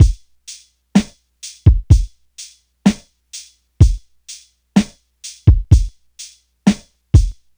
• 63 Bpm Drum Loop C Key.wav
Free breakbeat - kick tuned to the C note. Loudest frequency: 642Hz
63-bpm-drum-loop-c-key-WK8.wav